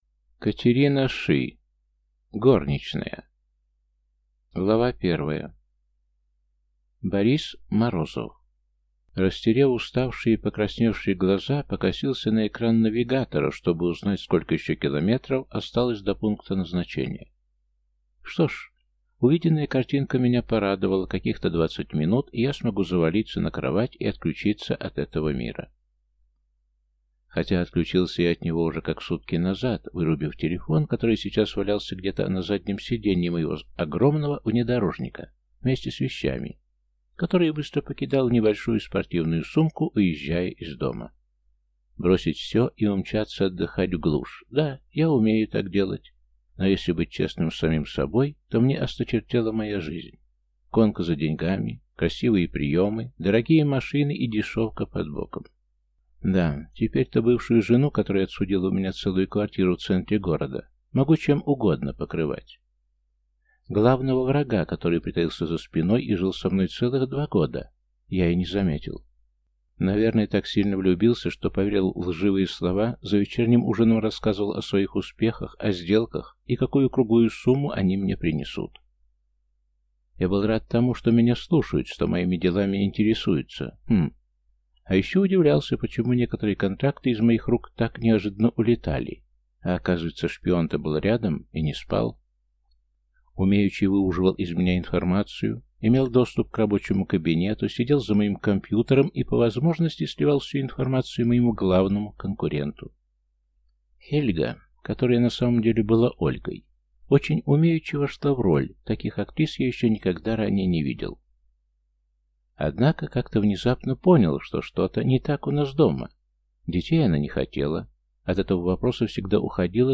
Аудиокнига Горничная | Библиотека аудиокниг